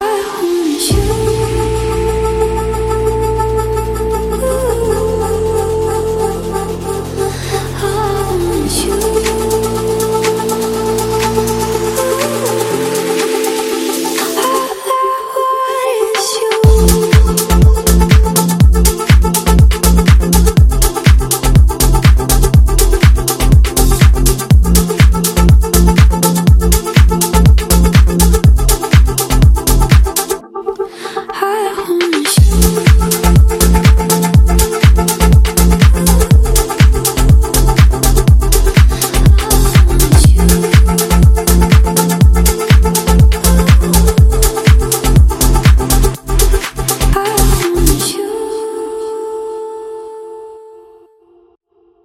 Танцевальные рингтоны , Громкие рингтоны